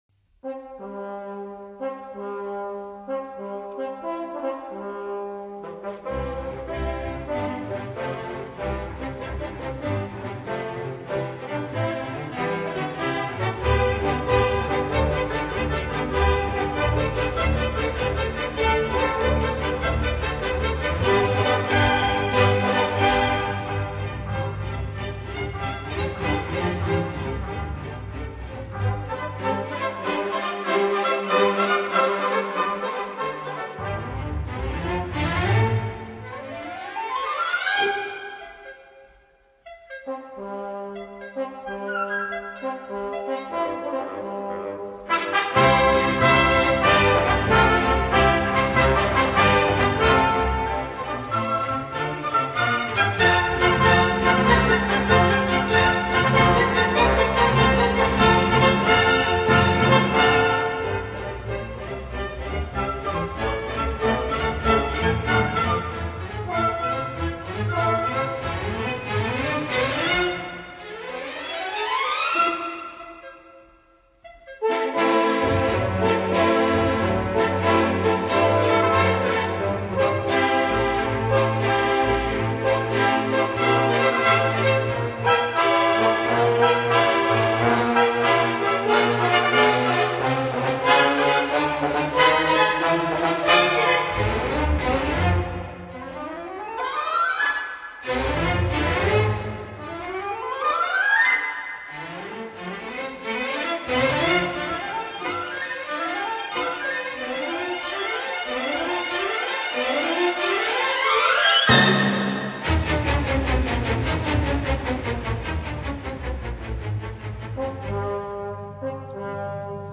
suite for orchestra
Frisch    [0:02:59.73]